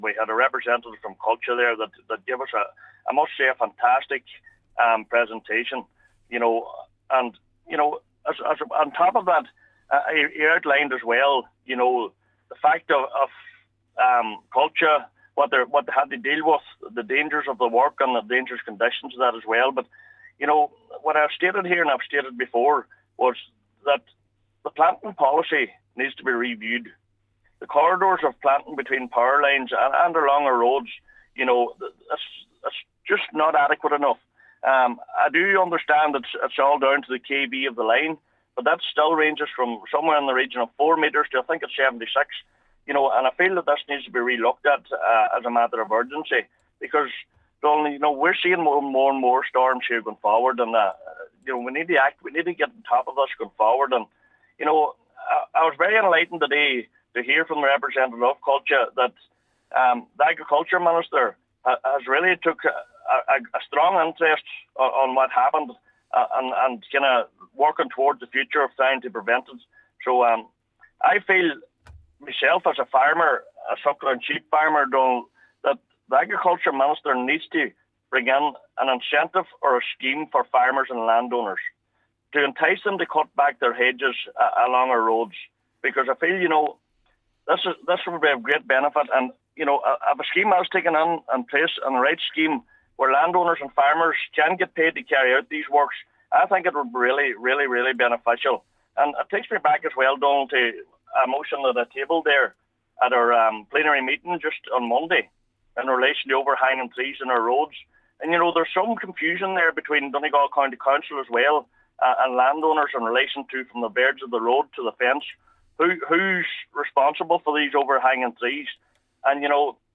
That’s according to Cllr Donal Mandy Kelly, who was speaking at a meeting of Donegal County Council’s agriculture committee.